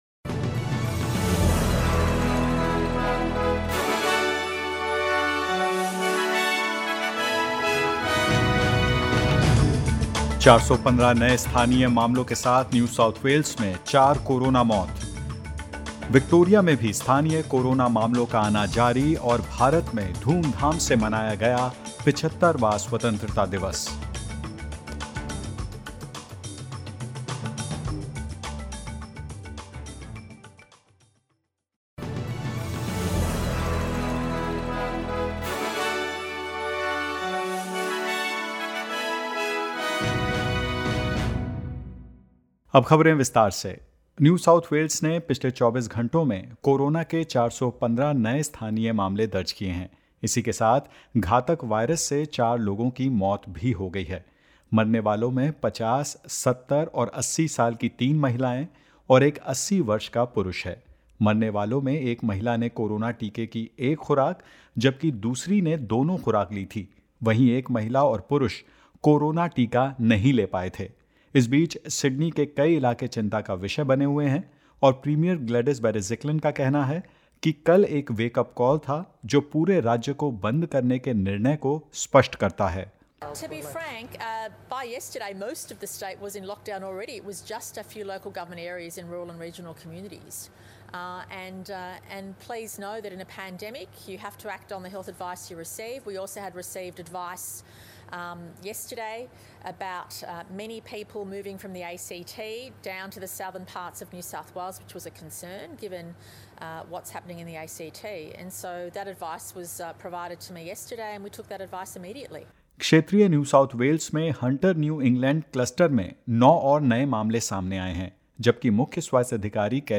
In this latest SBS Hindi News bulletin of Australia and India: One million extra doses of Pfizer due to arrive in Australia tonight; Two new locally acquired cases in ACT, and zero in QLD and more.